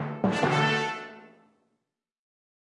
Media:RoyaleKing_base_dep.wavMedia:RoyaleKing_evo1_dep.wavMedia:RoyaleKing_evo2_dep.wav 部署音效 dep 在角色详情页面点击初级、经典、高手和顶尖形态选项卡触发的音效